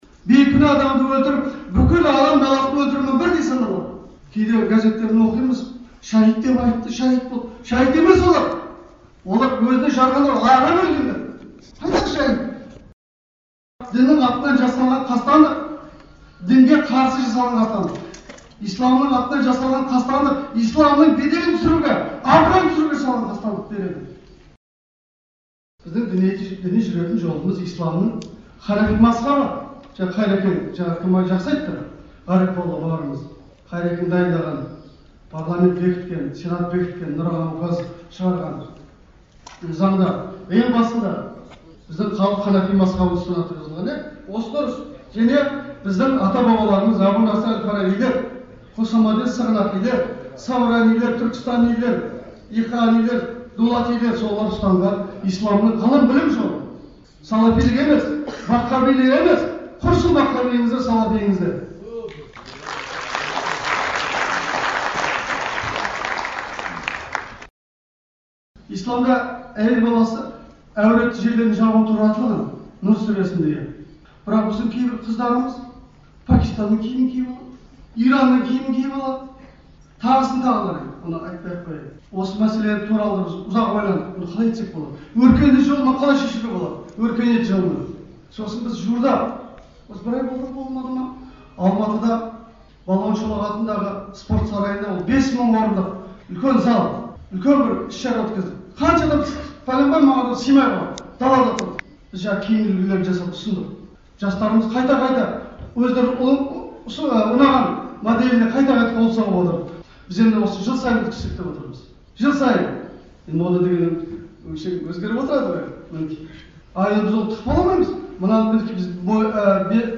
Әбсаттар қажы Дербісәлінің сөзінен үзінділер, Астана, 16 қараша 2011 ж.